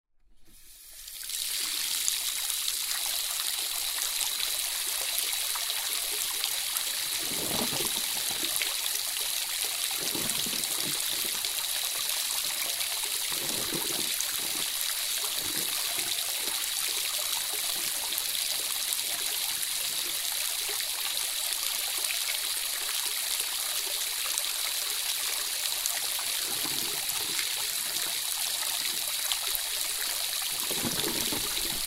Bathroom Sound Effects
Water_Basin_03.mp3